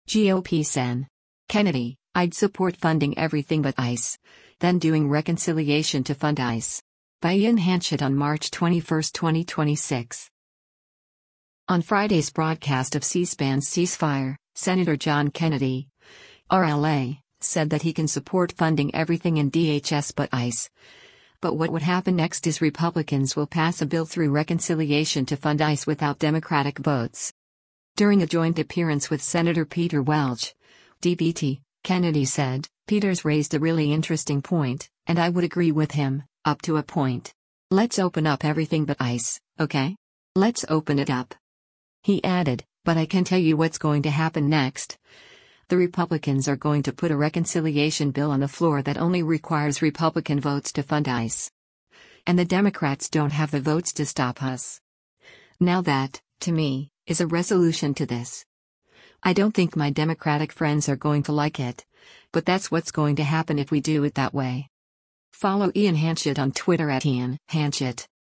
On Friday’s broadcast of C-SPAN’s “Ceasefire,” Sen. John Kennedy (R-LA) said that he can support funding everything in DHS but ICE, but what would happen next is Republicans will pass a bill through reconciliation to fund ICE without Democratic votes.